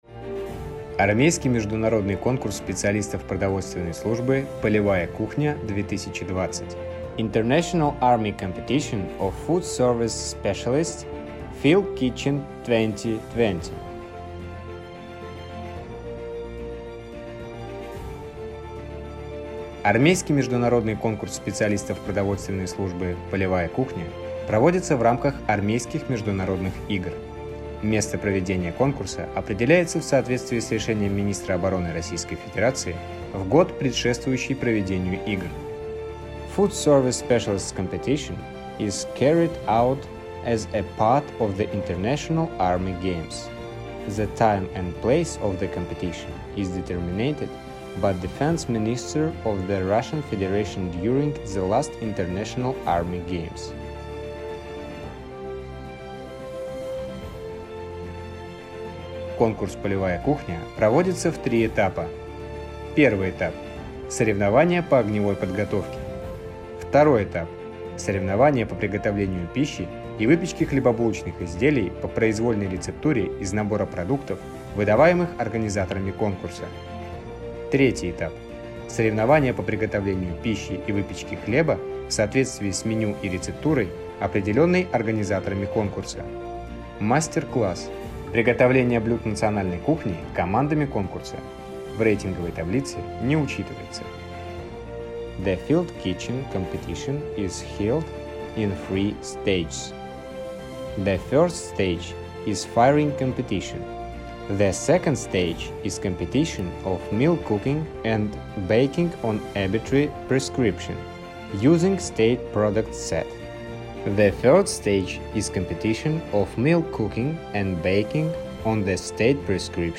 Аудио гид:
Armiigry-_Polevaya-kukhnya_-Audiogid.mp3